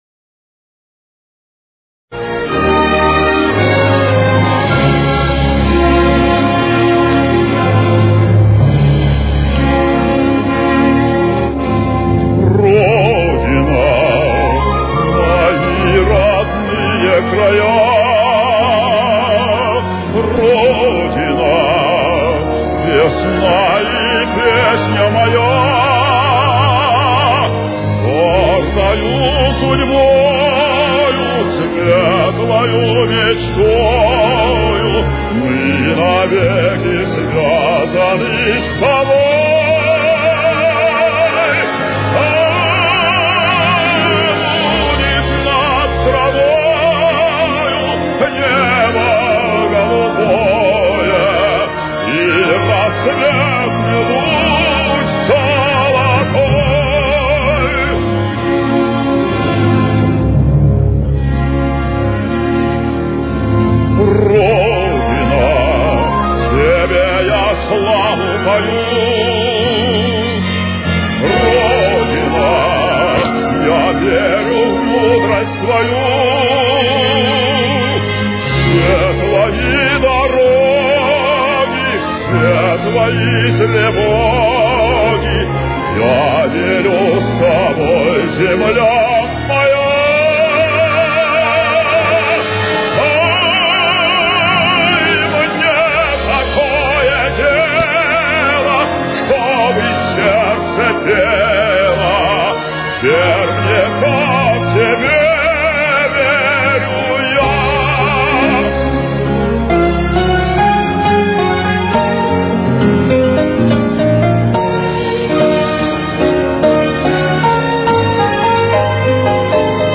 баритон